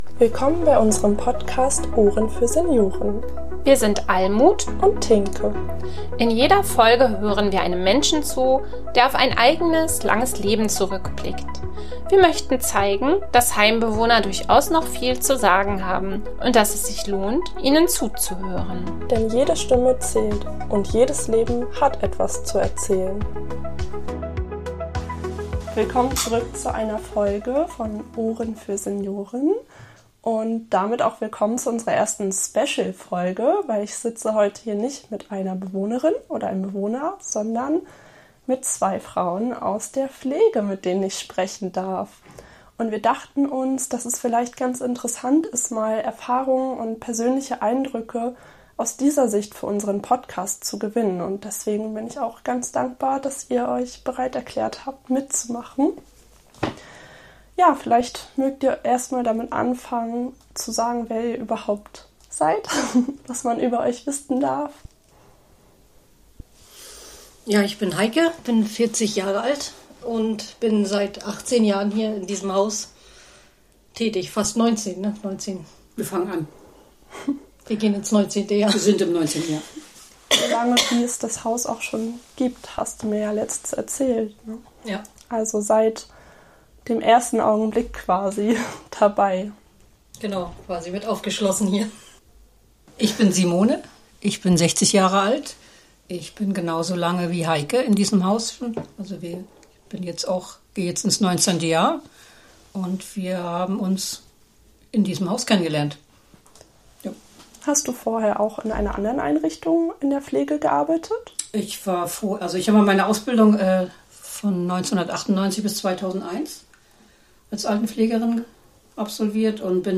In dieser besonderen Folge von Ohren für Senioren werfen wir einen persönlichen Blick hinter die Kulissen der Pflege. Zwei engagierte Pflegeexpertinnen sprechen über ihren besonderen Beruf, der fordert und gleichzeitig viel gibt.